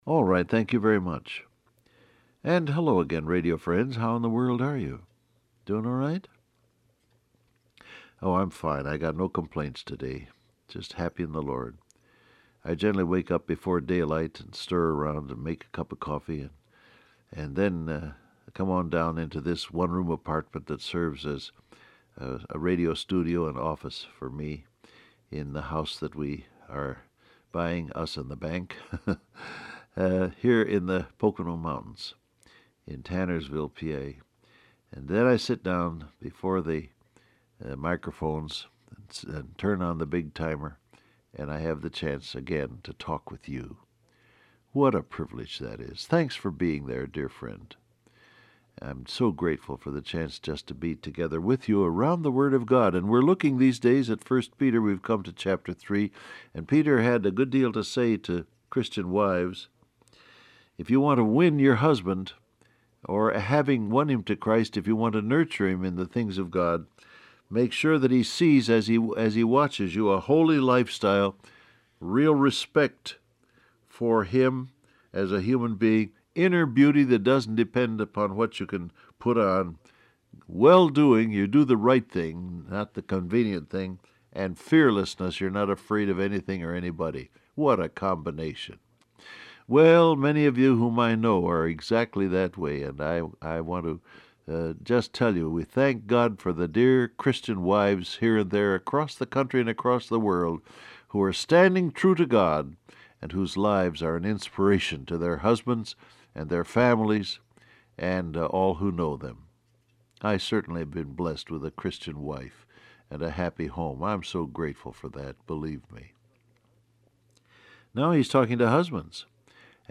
Download Audio Print Broadcast #7160 Scripture: 1 Peter 3:7 Topics: Heirs , Encourage , Grace , Praying , Honor , Husbands , Bless , Criticizing Transcript Facebook Twitter WhatsApp Alright, thank you very much.